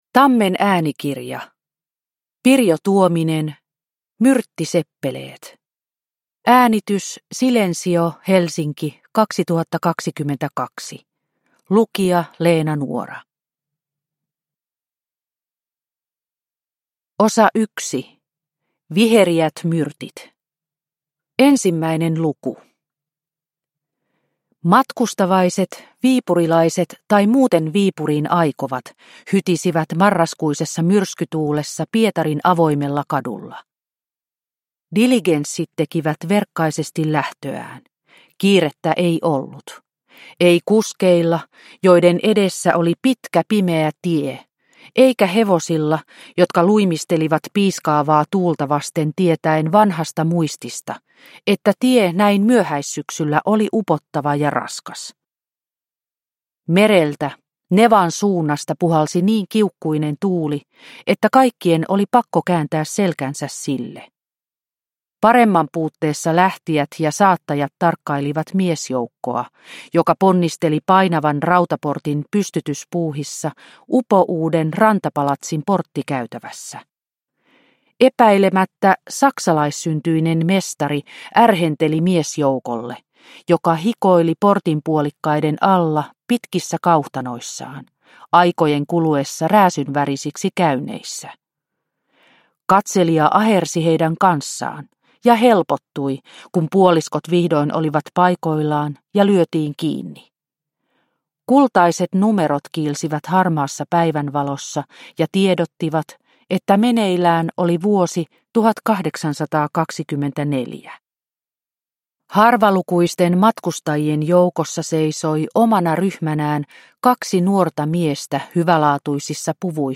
Myrttiseppeleet – Ljudbok – Laddas ner